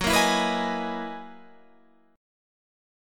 F#13 chord